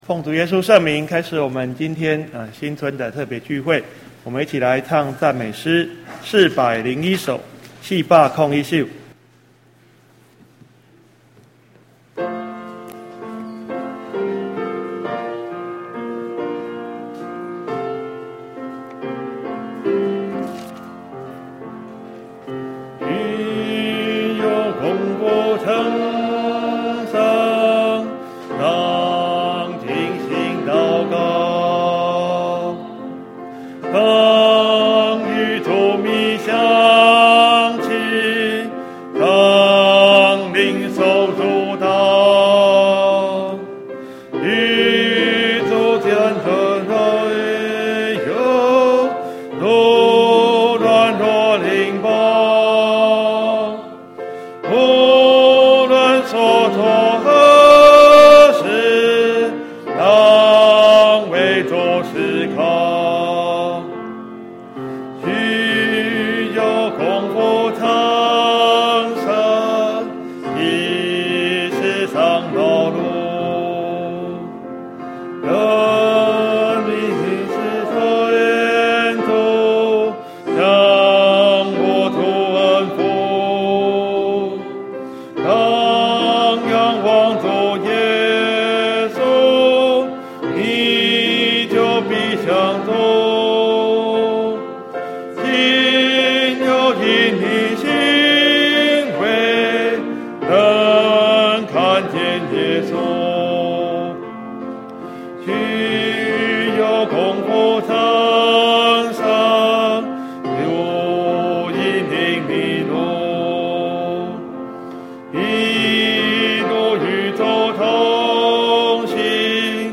新春特別聚會